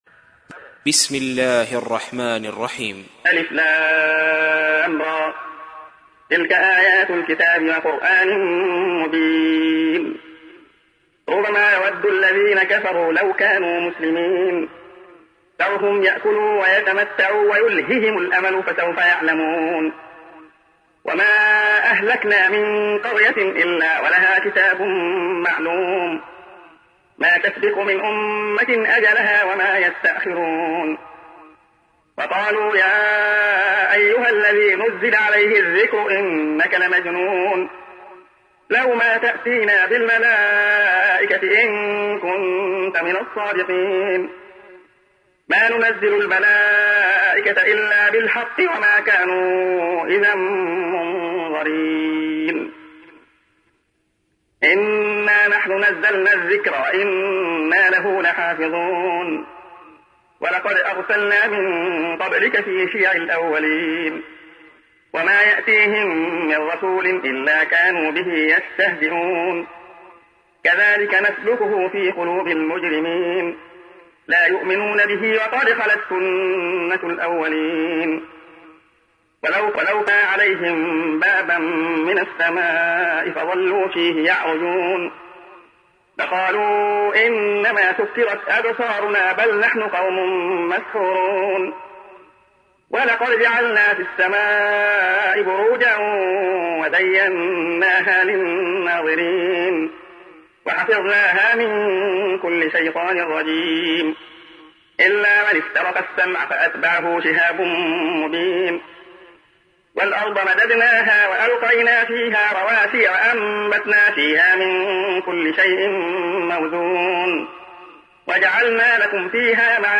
تحميل : 15. سورة الحجر / القارئ عبد الله خياط / القرآن الكريم / موقع يا حسين